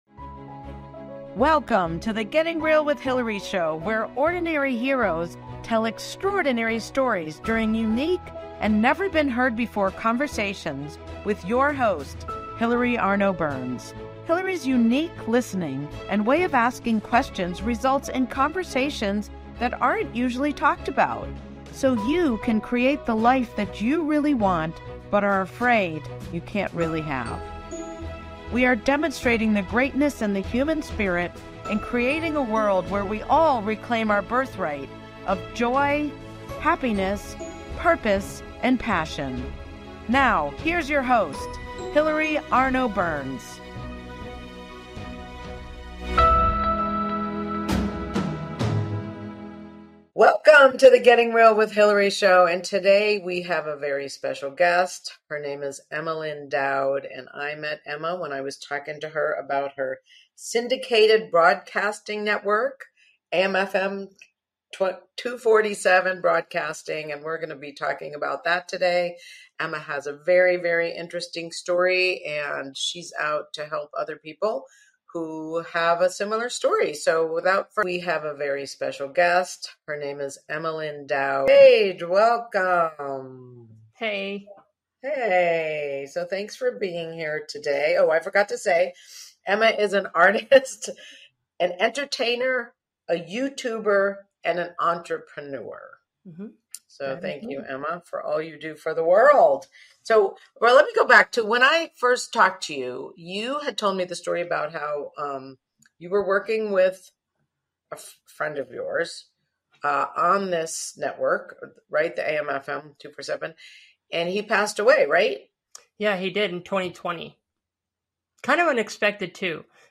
This conversation had me nervous. I didn't understand terms like intersex, gender fluid, transgender, why people say they, and gender dysphoria.